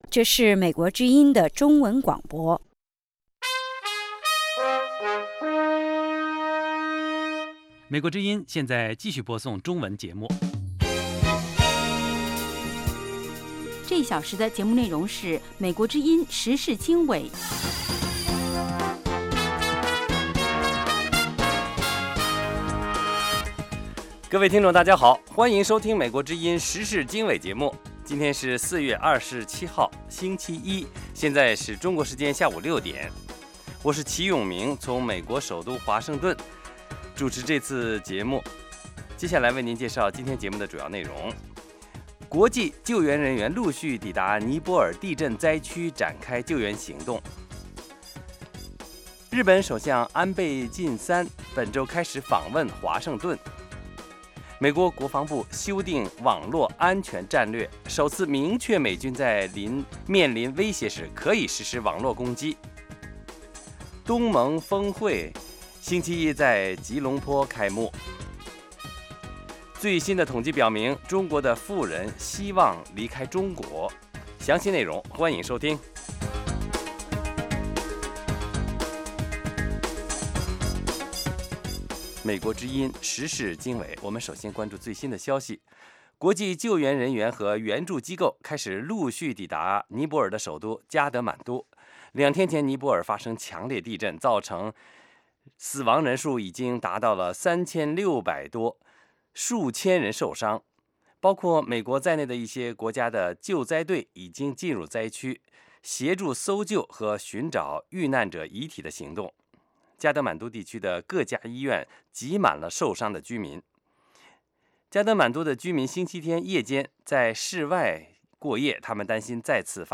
北京时间晚上6-7点广播节目